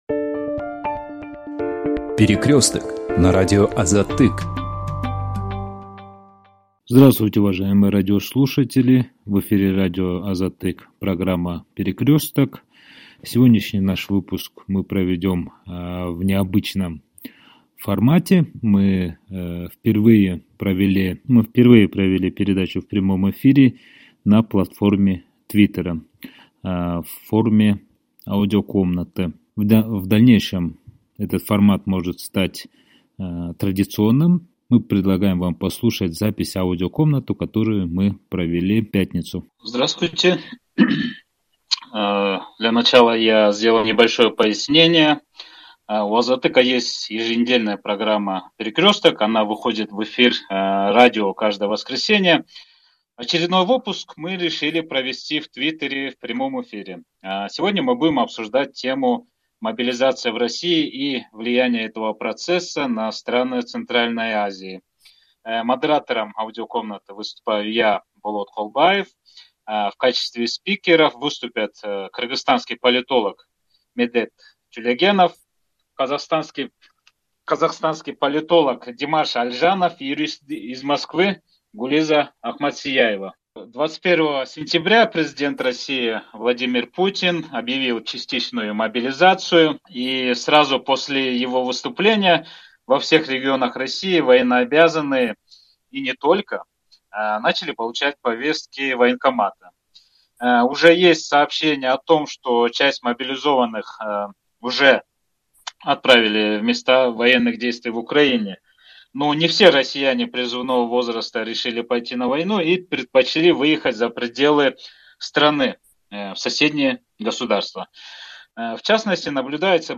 В дискуссии приняли участие кыргызстанский политолог